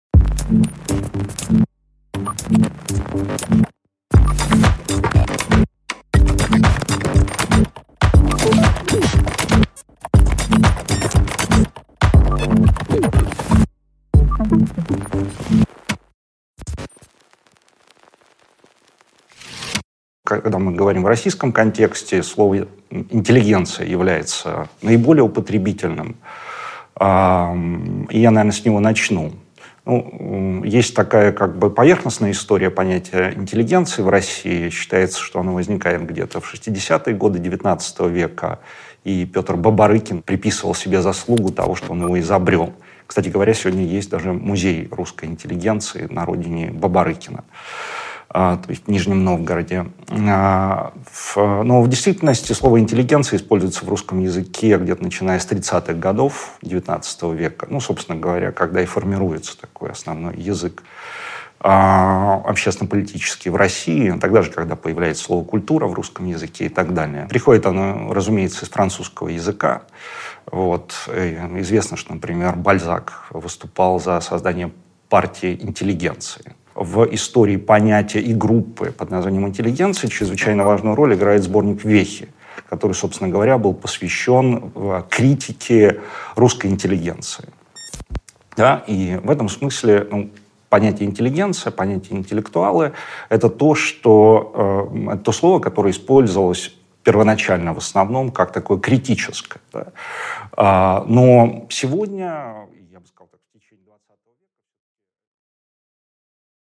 Аудиокнига Производство идеологии | Библиотека аудиокниг